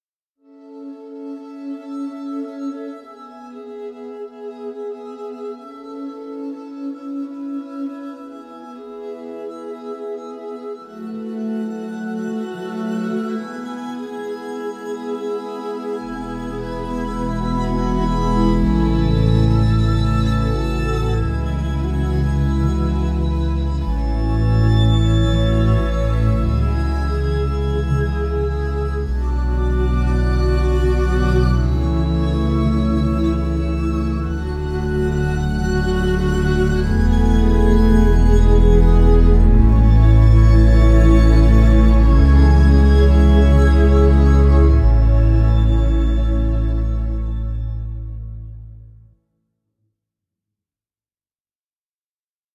弦乐独奏
通过 8 个麦克风阵列以 96kHz 采样，每个演奏家都单独录制，以便进行精细控制。
- 预设：独奏小提琴、独奏中提琴、独奏大提琴
- 发音：软弦乐刺激、交错颤音、泛音口哨、偶发颤音大二度、偶发颤音大三度